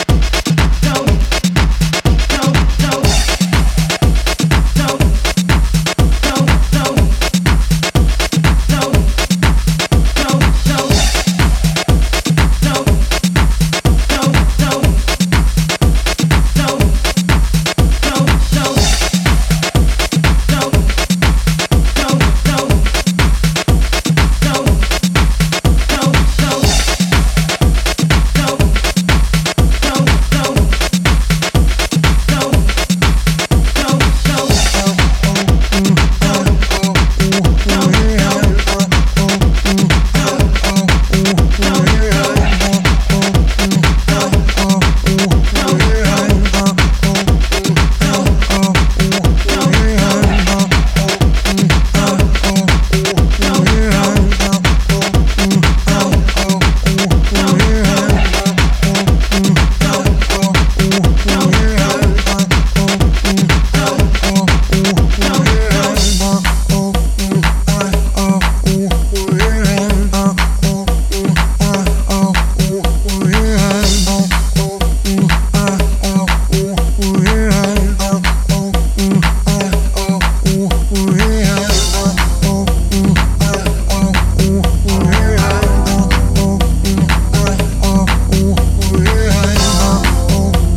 etc. It is a fat house tune with excellent stability